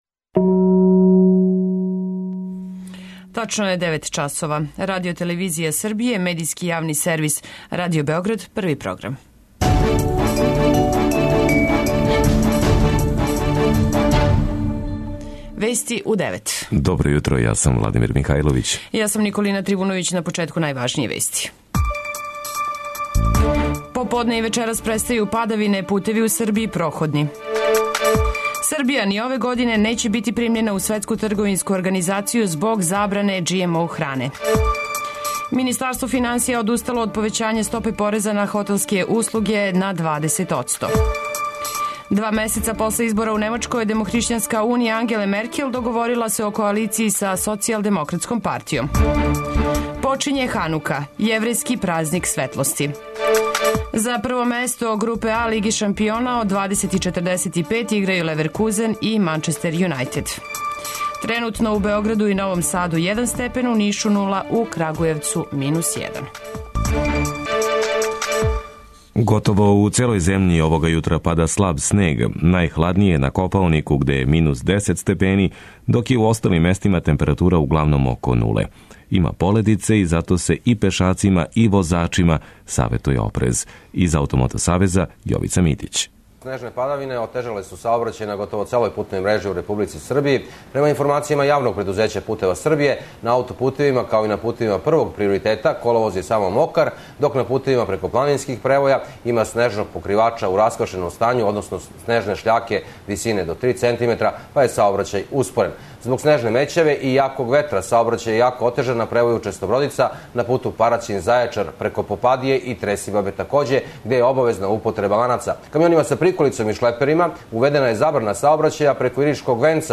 Кошаркаши Радничког од 19 часова гостују Нилан Бизонсима у Финској, у мечу седмог кола Еврокупа. преузми : 10.34 MB Вести у 9 Autor: разни аутори Преглед најважнијиx информација из земље из света.